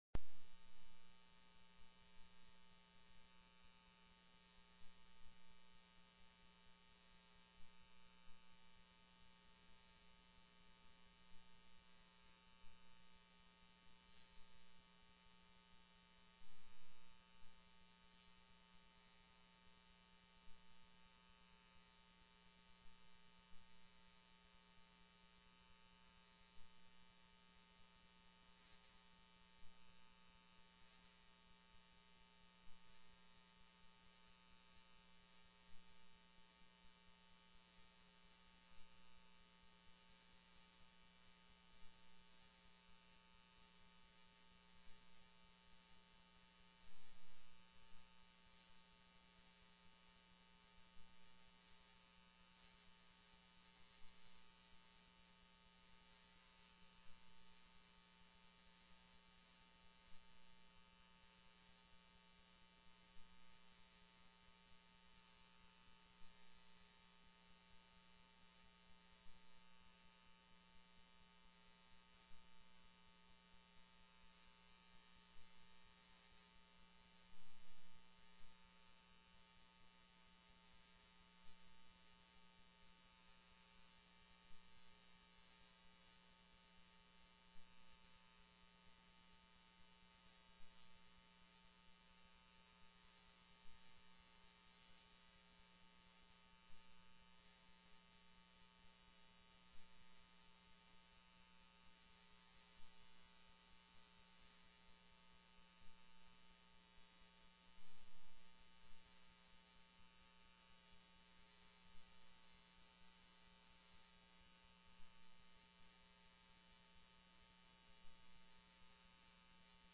Public Testimony -- += HB 83 APPROP: MENTAL HEALTH BUDGET
PRESENT VIA TELECONFERENCE